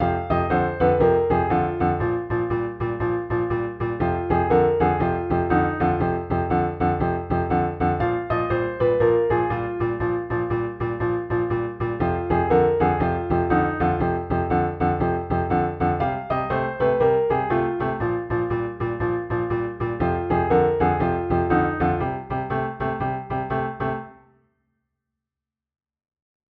Blues Soloing
The notes of the blues scale are played over a 12 bar blues in the key of F with the left hand playing a shuffle rhythm.
The notes of Pattern 1 just move down the blues scale starting on F. Pattern 2 starts on the root F as well, but moves down again after reaching the minor third Ab, and then moves down to Eb and back to F.
f-blues-with-blues-scale-patterns.mp3